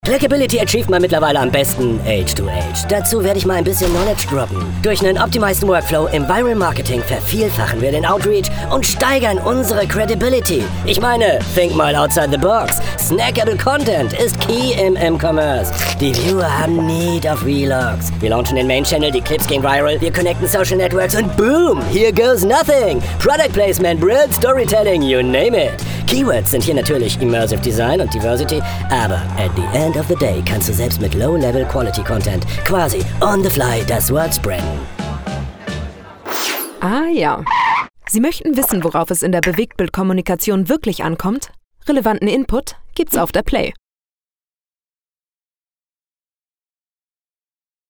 Animación
Cabina de voz: Desone
Micrófono: Austrian Audio OC 18
Preamplificador: golden age pre 73 mk iii